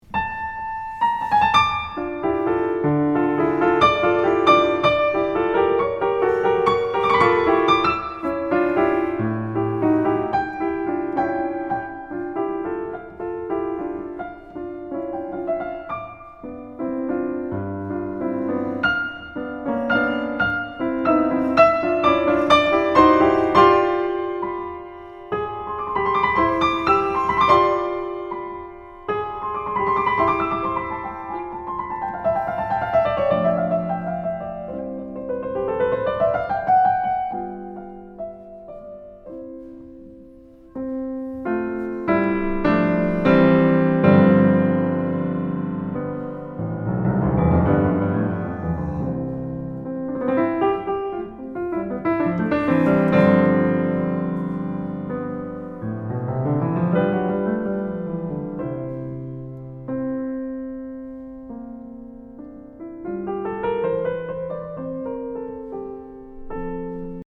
But when the second theme enters in d-minor, the stress is on the first bar instead:
The two themes are so incredibly contrasting, also in their inner life: while the left hand creates nice “cushions” on the first beat in the bar for the first theme, the accompaniment figure (from the first movement) in the second theme is making it impossible to find any calm.